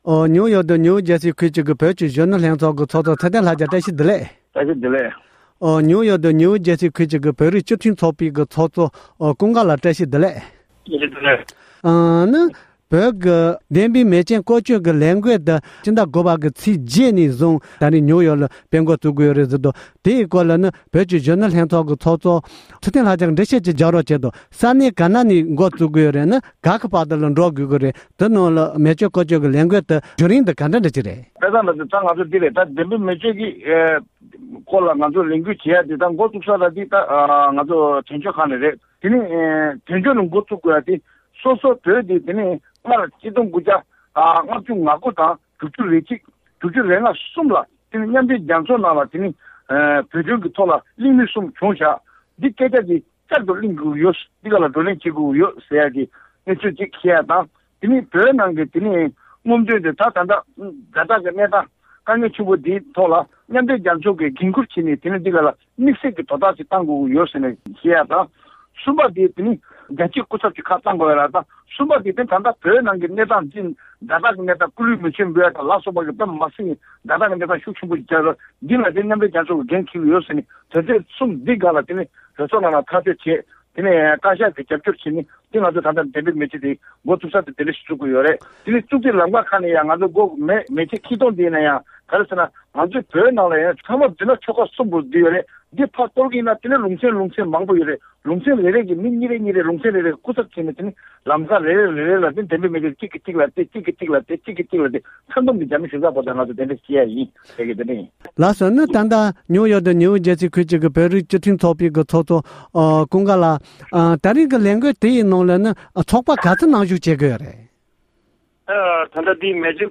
སྒྲ་ལྡན་གསར་འགྱུར། སྒྲ་ཕབ་ལེན།
བཅར་འདྲི